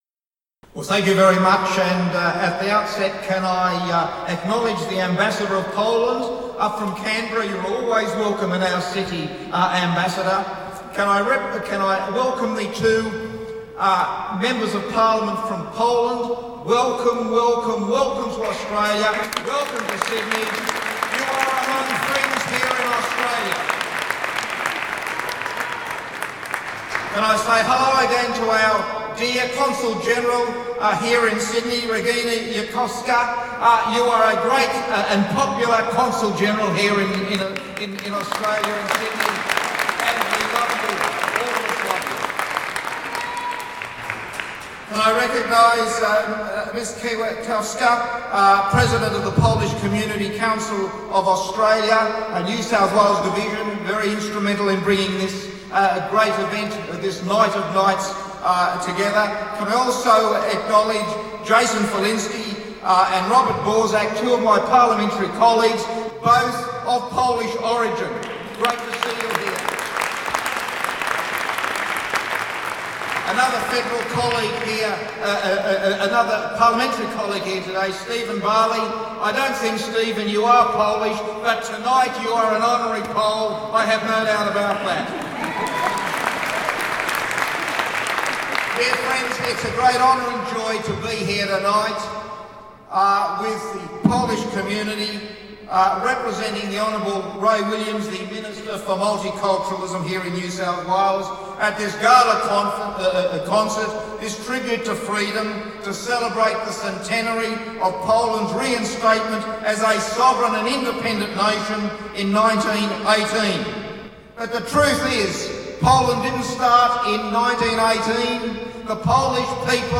Tribute to Freedom w sydnejskim Ratuszu
A speech by Hon. David Clarke.
ClarkeDavidSpeech.mp3